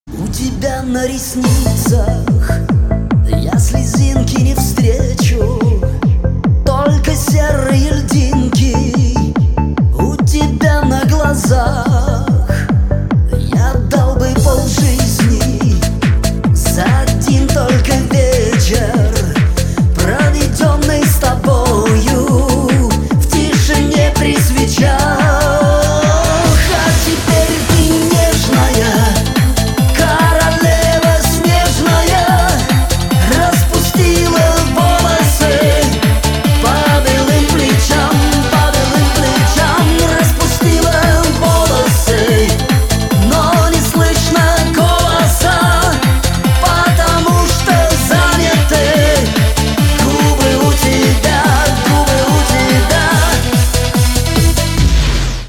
• Качество: 128, Stereo
попса
клубняк